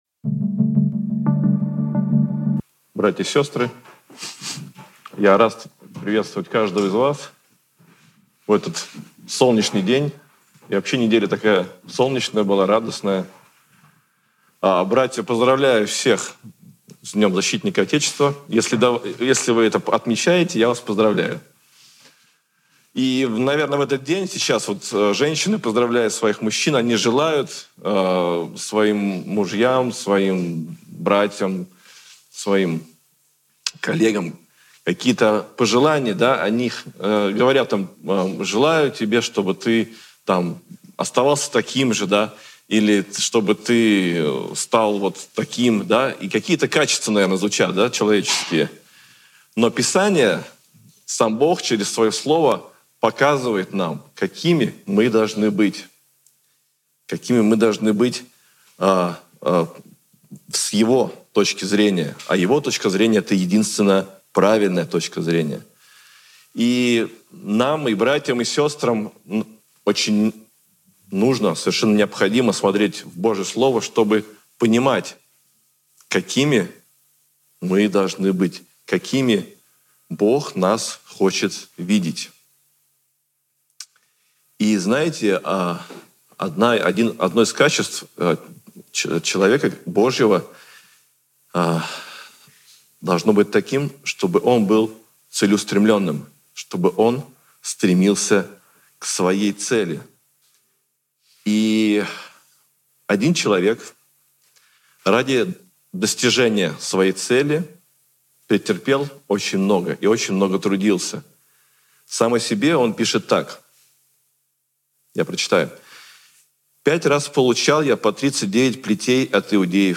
«Преображение» | Церковь евангельских христиан-баптистов